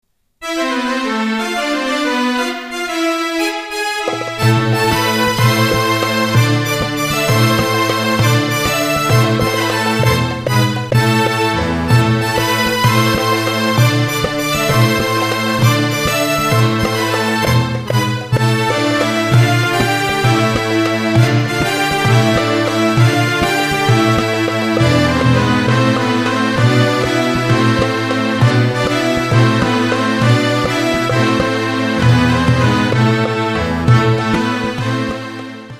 ARA VAI VAI - Upbeat 6/8. Armenian.